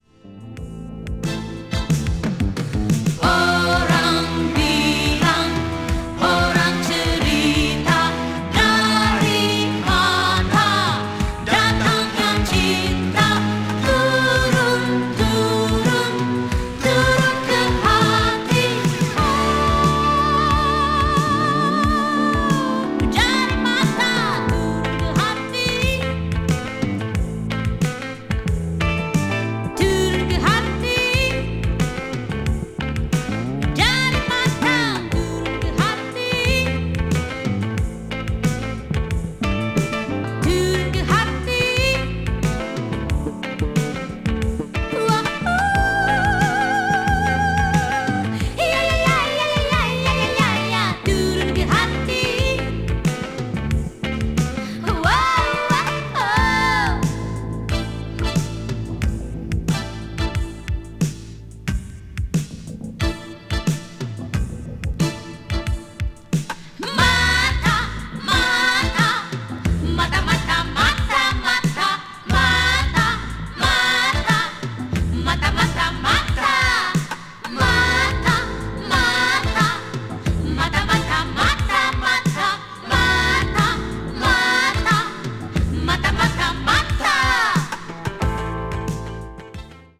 media : EX/EX(some slightly noise.)
keroncong   obscure dance   south east asia   world music